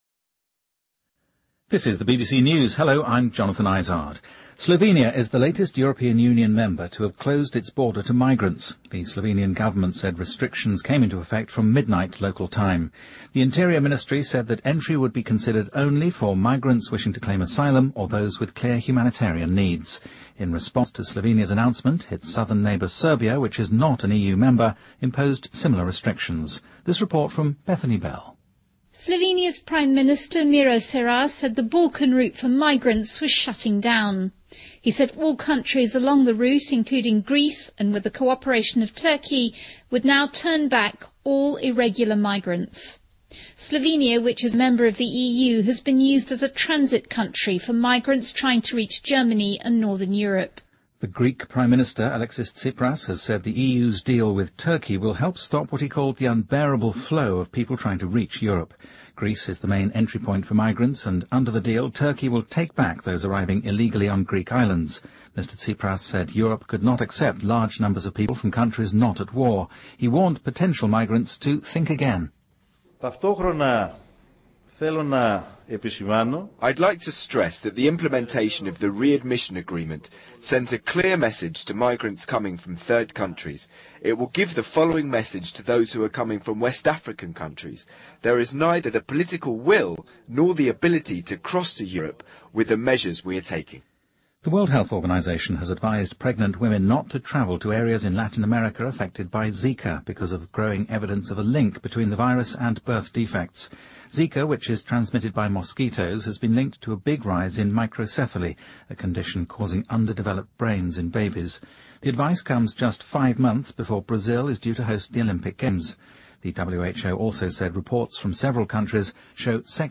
BBC news,世卫建议孕妇不要前往寨卡病毒高发地区